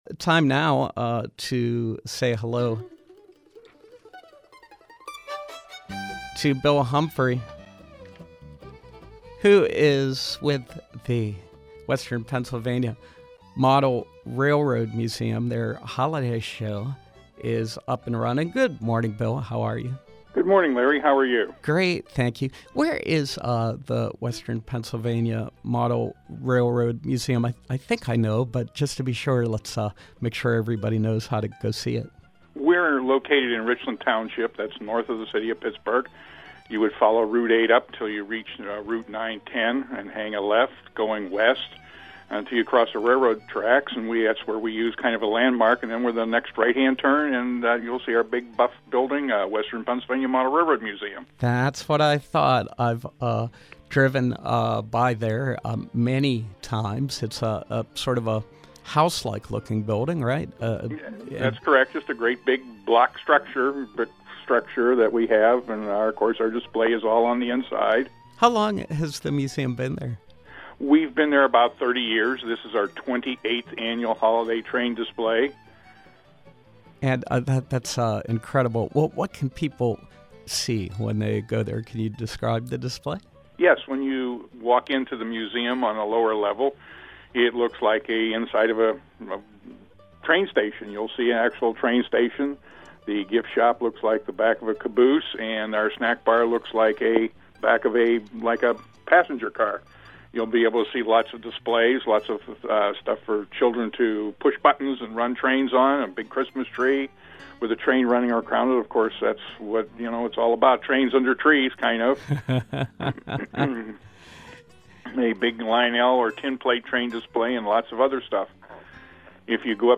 Interview: Western Pa. Model Railroad Museum